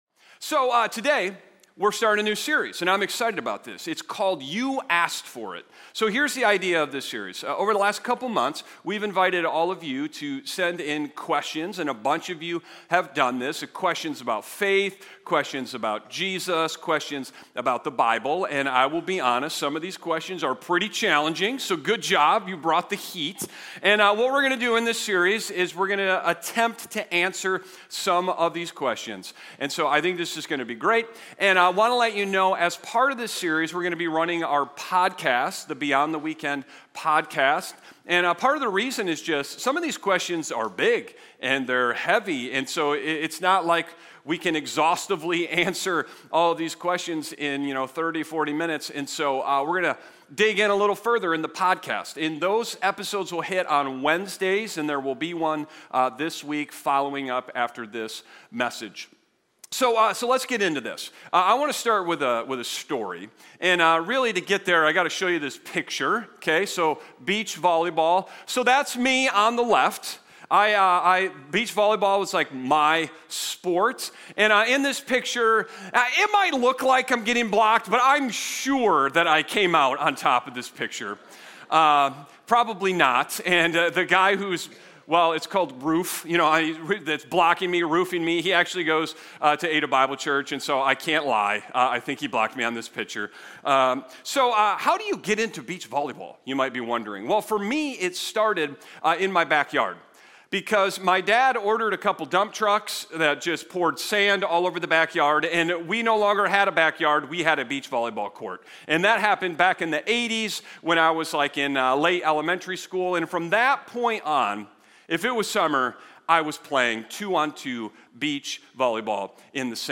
Sermon Discussion